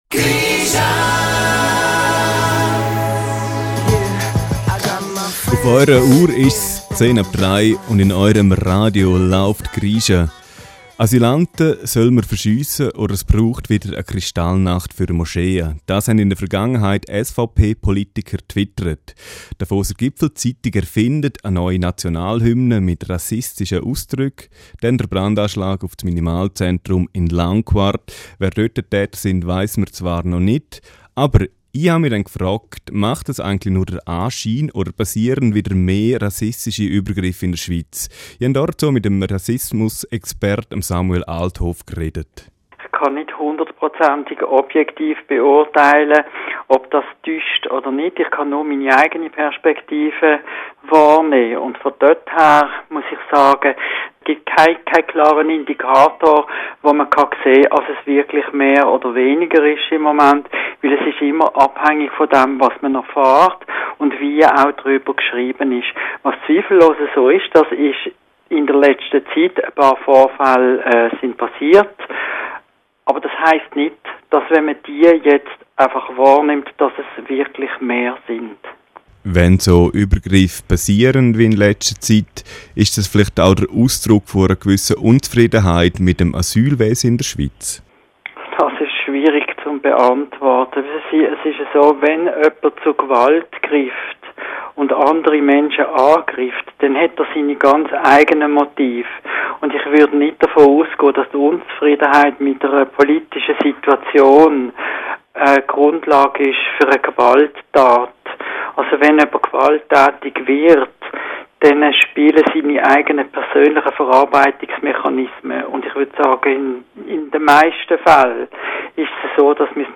Ein Gespräch
Interview